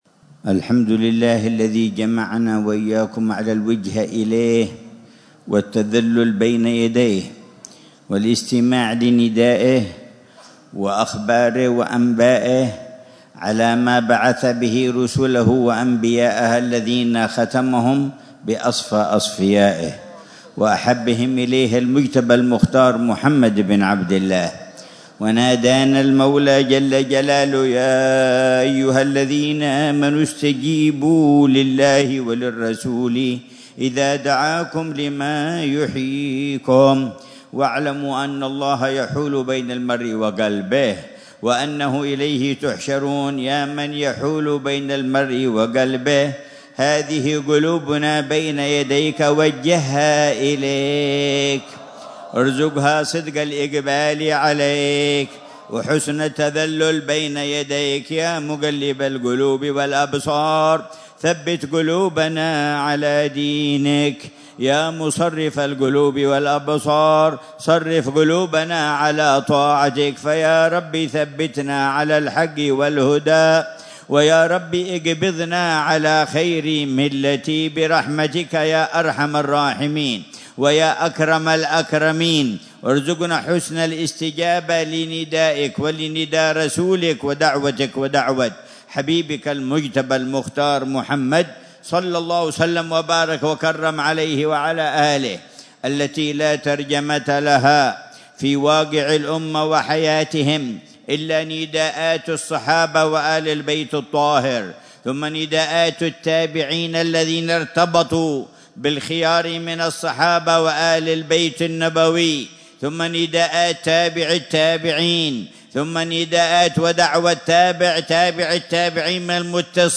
مذاكرة العلامة الحبيب عمر بن محمد بن حفيظ في مجلس الذكر والتذكير في ذكرى دخول سيدنا المهاجر إلى الله أحمد بن عيسى إلى حضرموت، في منطقة الحسيسة، ليلة الخميس 15 محرم 1447هأ بعنوان: